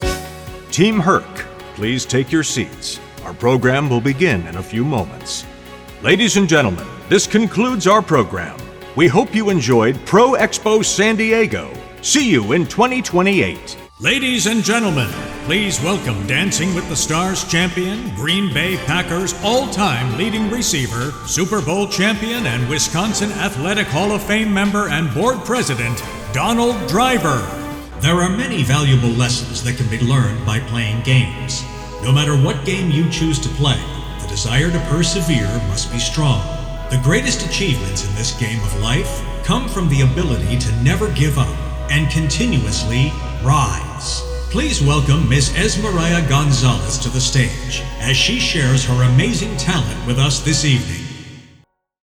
Anuncios
His voice has been described as Articulately Conversational.
Neumann TLM-103
Custom designed broadcast quality Whisper Room
Mediana edad